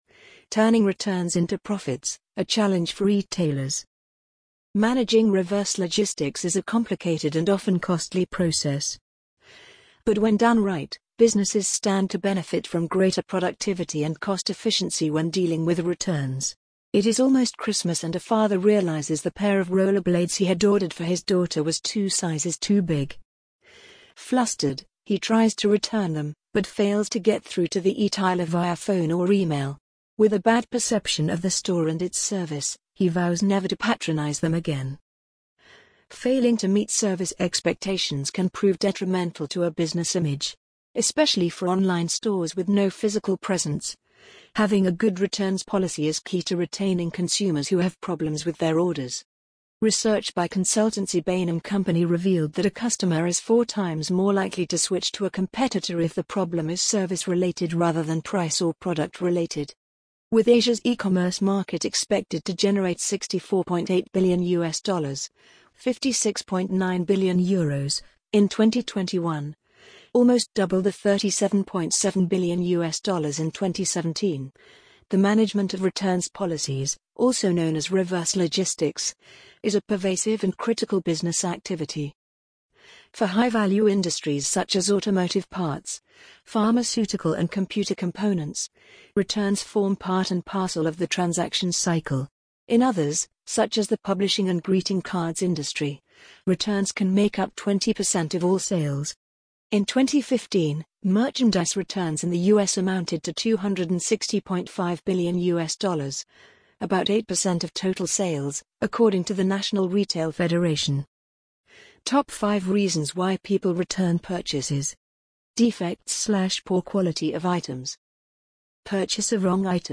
amazon_polly_2254.mp3